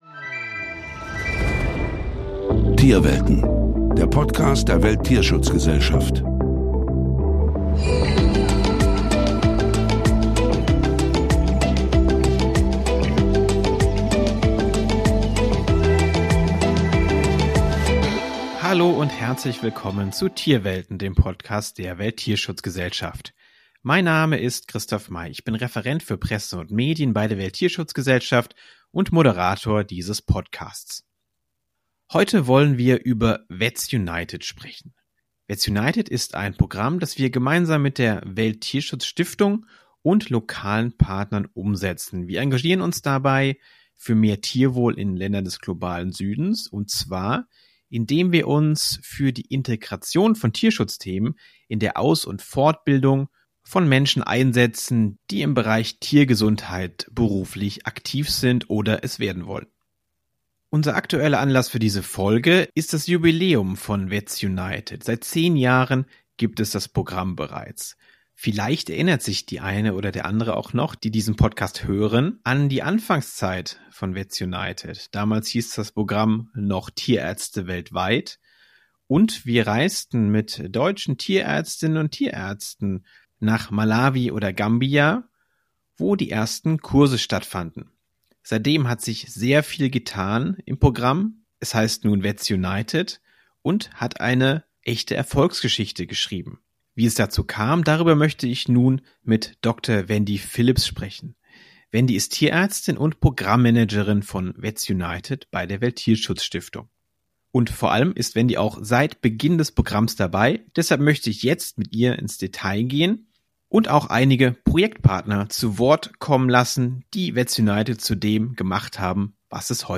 Auch zwei langjährige Wegbegleiter des Programms aus Uganda und Gambia kommen in dieser Folge zu Wort und schildern ihre Erfahrungen mit VETS UNITED.